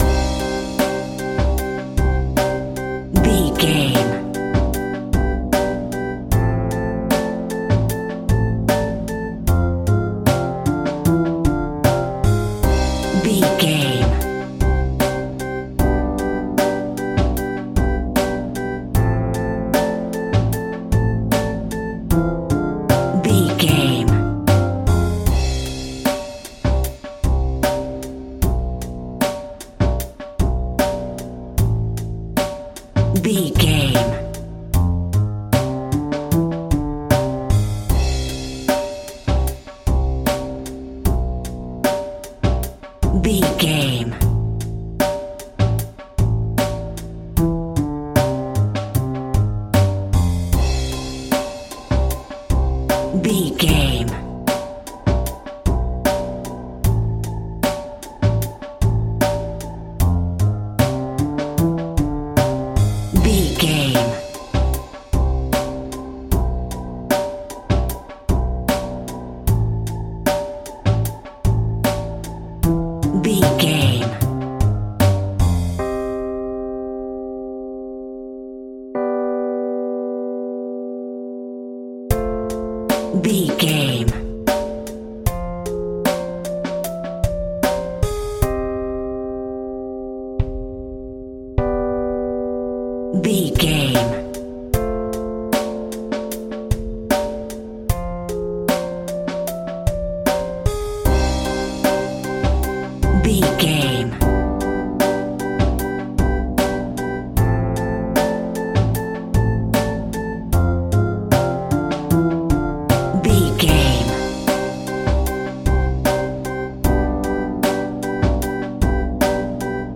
Aeolian/Minor
scary
tension
ominous
dark
suspense
haunting
eerie
organ
electric organ
drums
strings
synth
pads